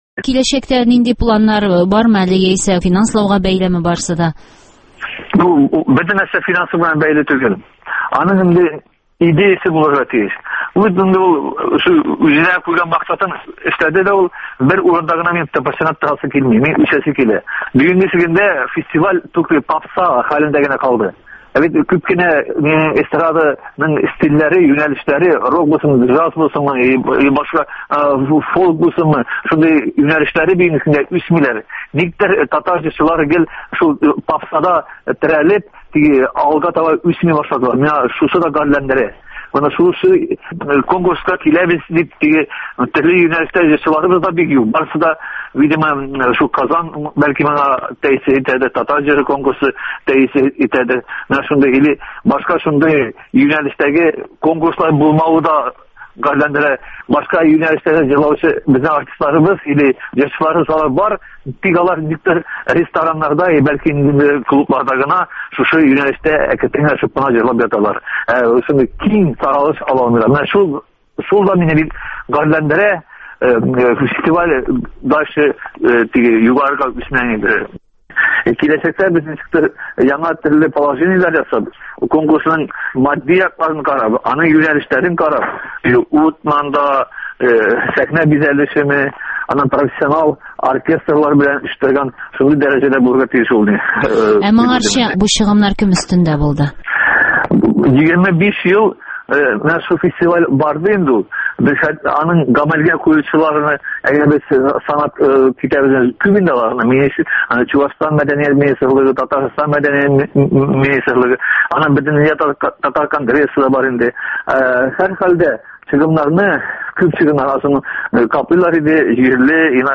әңгәмә